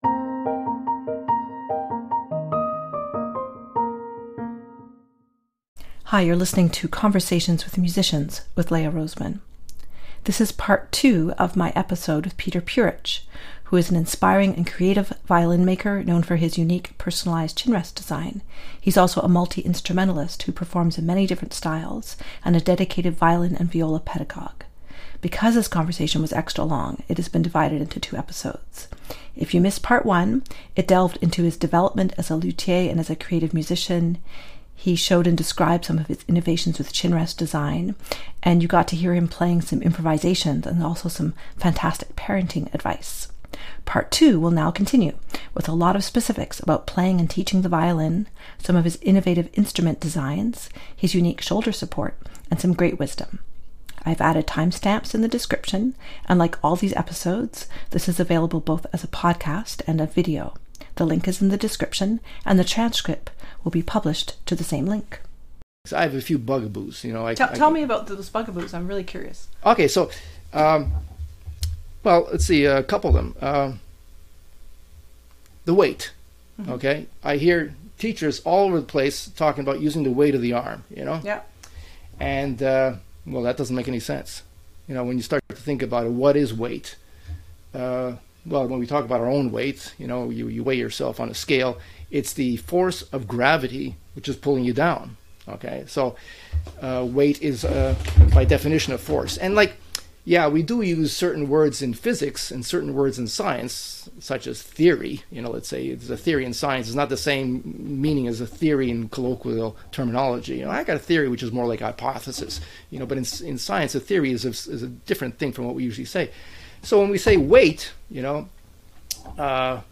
Because this conversation was extra-long it has been divided into two episodes. Part 2 continues with a lot of specifics about playing and teaching the violin, and he describes some of his innovative instrument designs, his unique shoulder support, and some great wisdom.